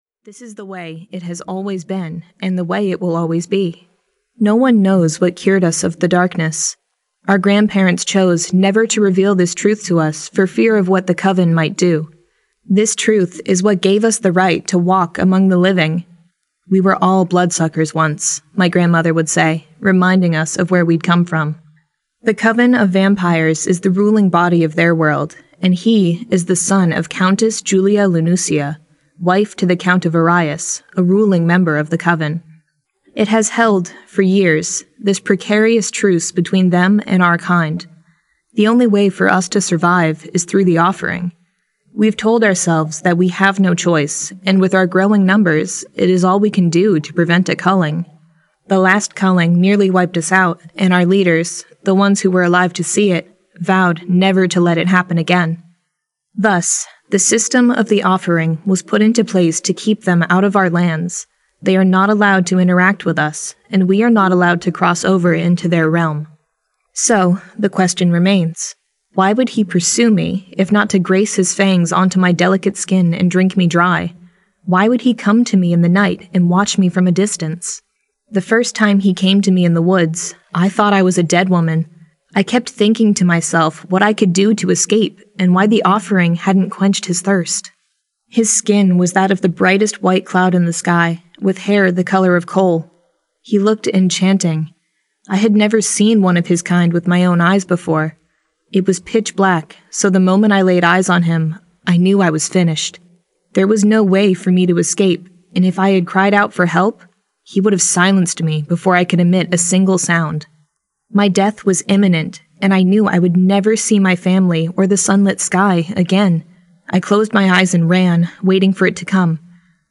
Courting Moon Audiobook | (The Blood's Passion Saga Book 1) 1st Edition